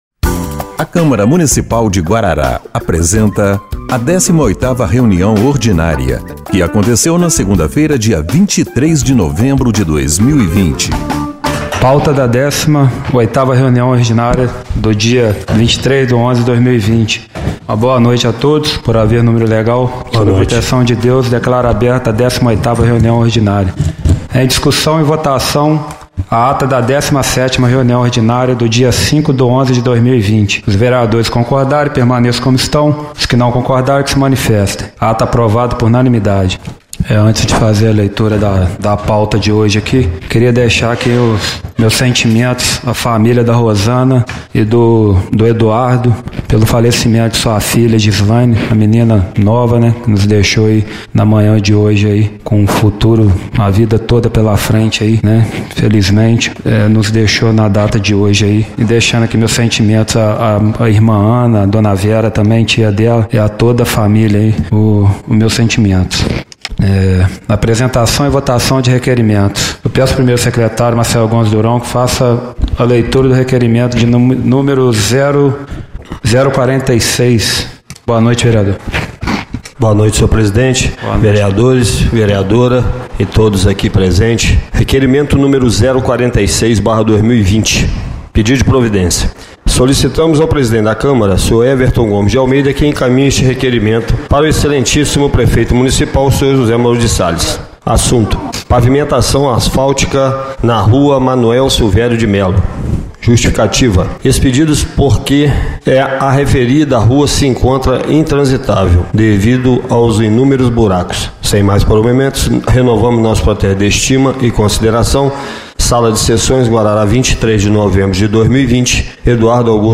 18ª Reunião Ordinária de 23/11/2020